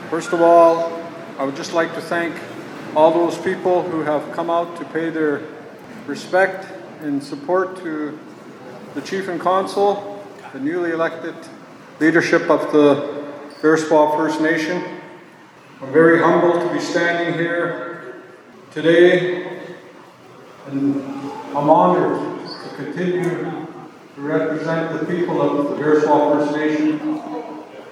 Bearspaw First Nation held an Inauguration Ceremony for their elected Chief and Council
During a speech at the Inauguration, Chief Dixon expressed his thanks.